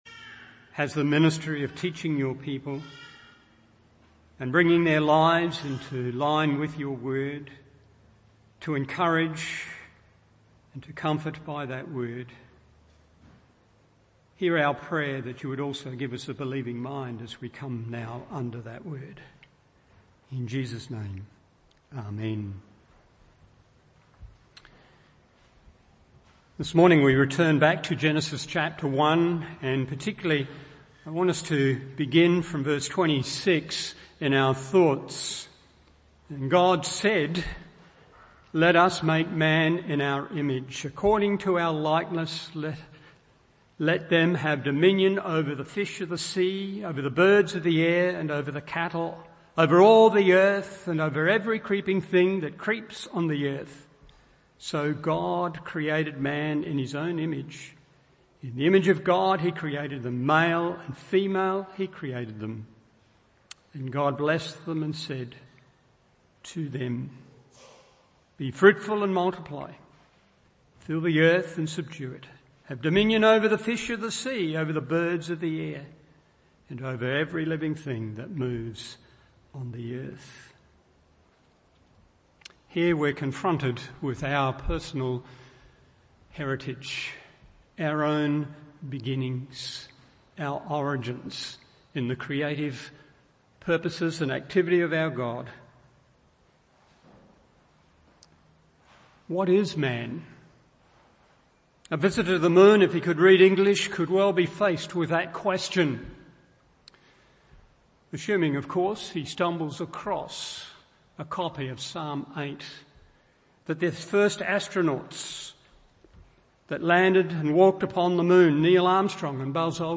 Morning Service Genesis 1:26-31 1. The Object of God’s Special Interest 2. The Focus of God’s Special Activity 3. The Recipient of God’s Special Commission…